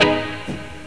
RIFFGTR 15-L.wav